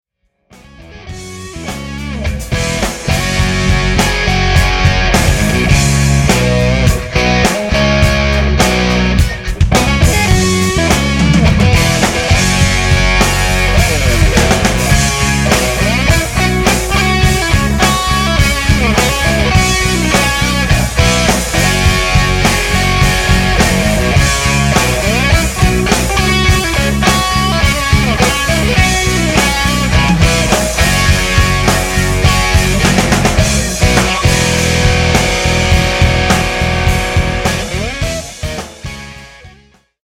Hard rocking tour d’force!
No Lyrics, this is an instrumental song.